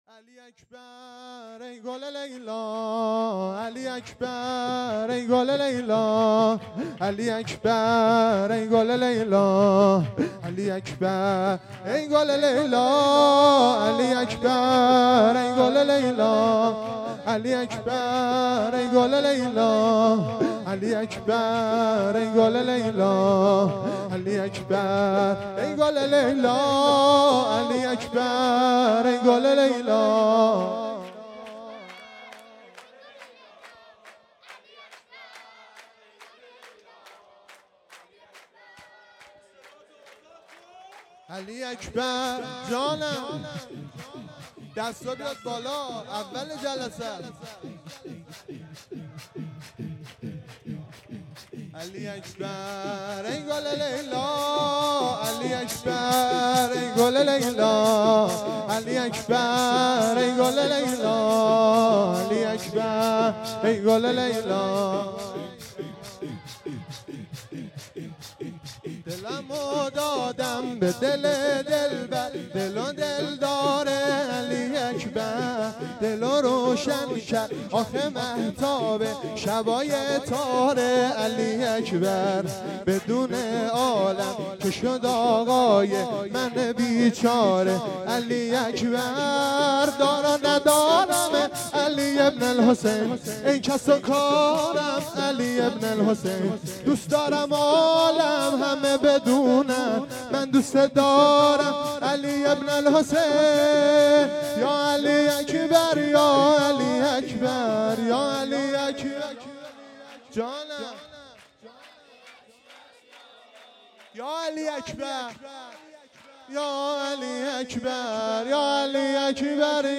شور
جشن میلاد حضرت علی اکبر(ع)1398